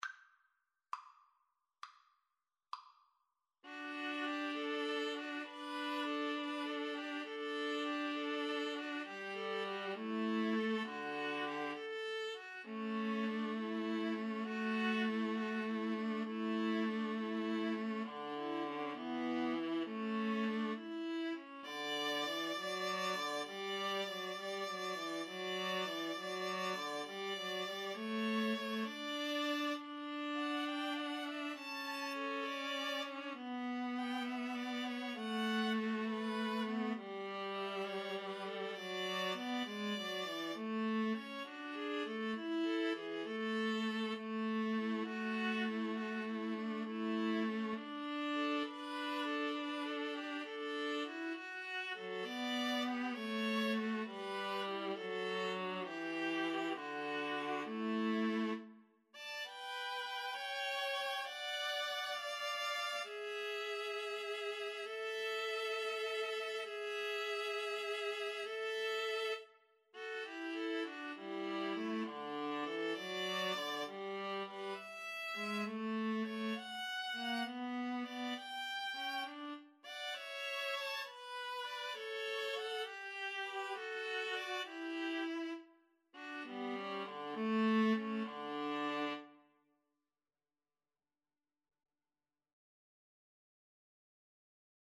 Free Sheet music for Viola Trio
D major (Sounding Pitch) (View more D major Music for Viola Trio )
Largo
Viola Trio  (View more Intermediate Viola Trio Music)
Classical (View more Classical Viola Trio Music)